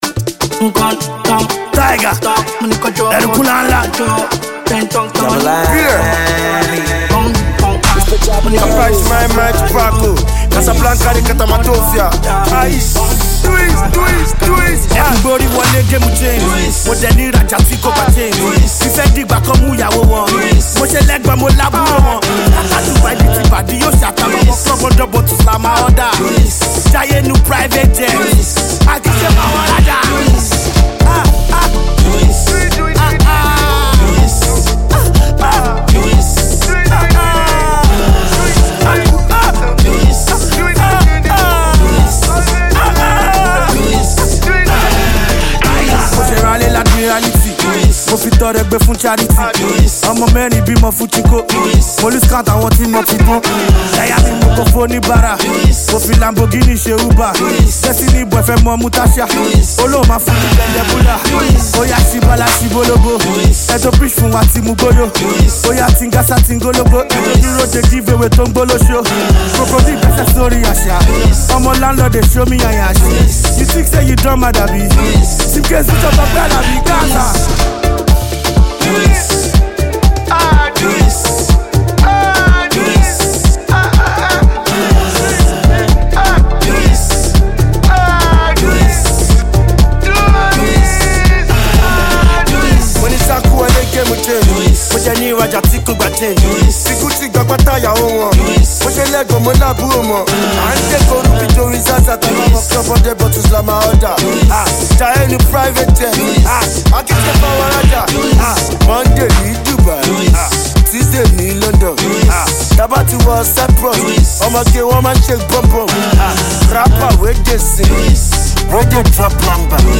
street banger
Indigenous Rap artist